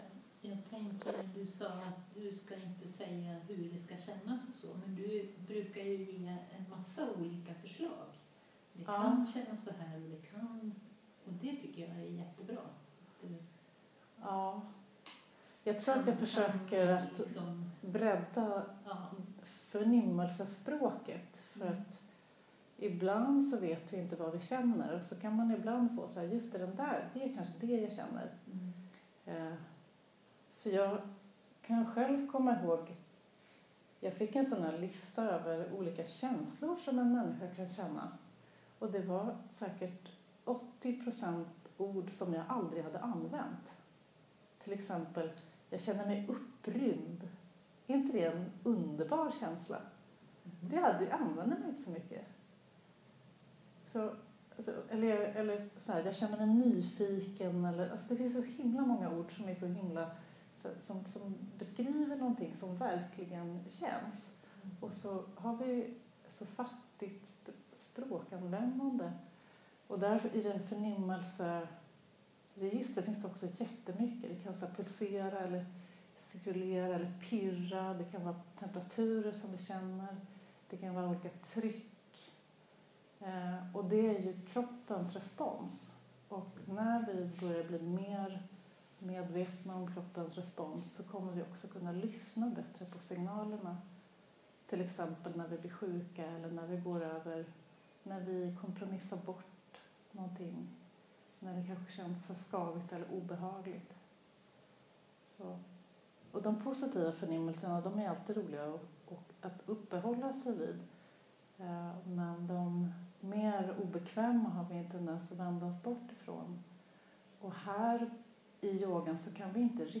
Yinklass söndag 5 april
Ljudupptagning direkt från klass. OBS – varierande kvalitet på inspelningarna.